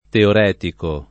teoretico [ teor $ tiko ] agg.; pl. m. ‑ci